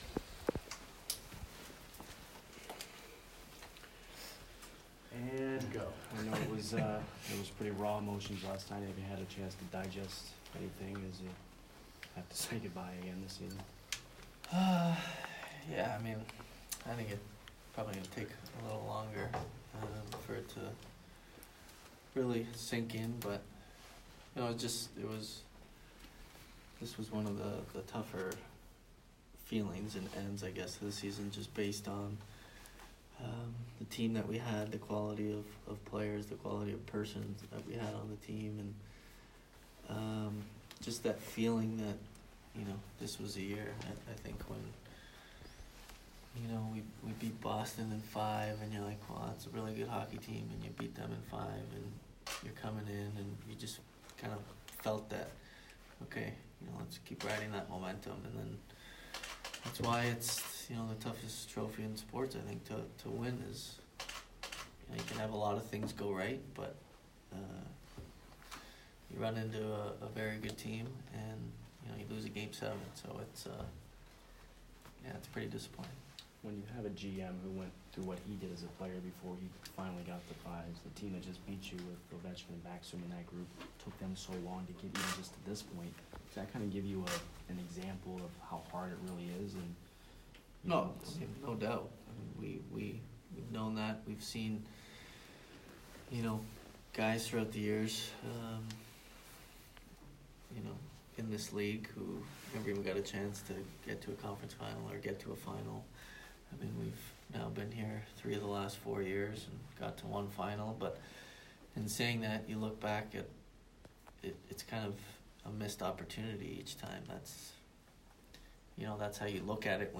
Steven Stamkos Exit Interview 5/24